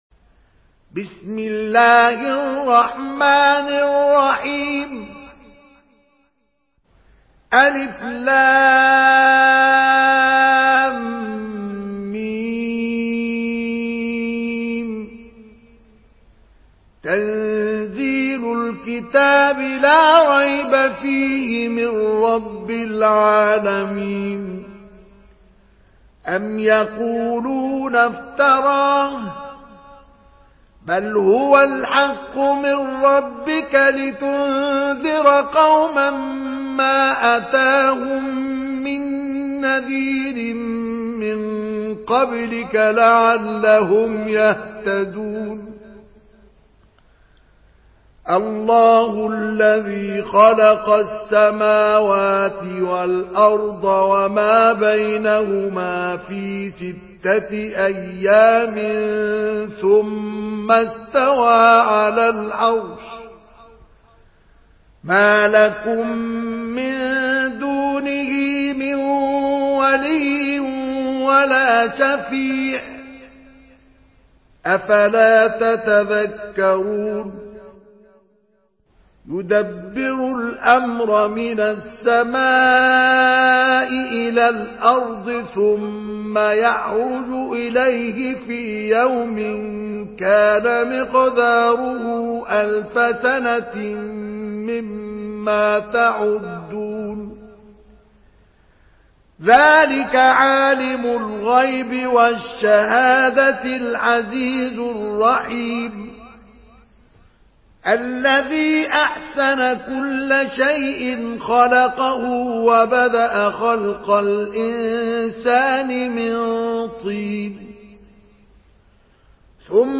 سورة السجدة MP3 بصوت مصطفى إسماعيل برواية حفص
مرتل حفص عن عاصم